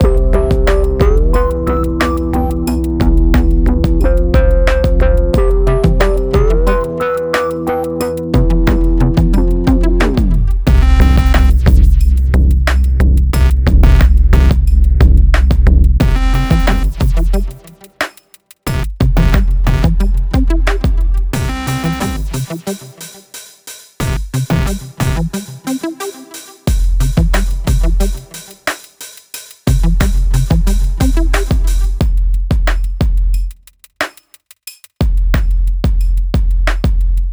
In this problem, you should write a program that can play all of the three dubstep tracks from problem 1.